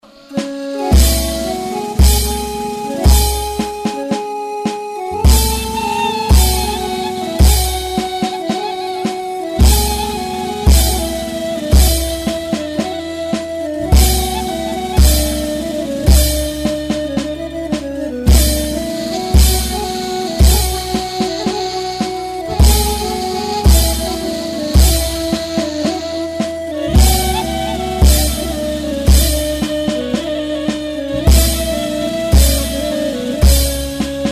زنگ-موبایل-زیبا-224.mp3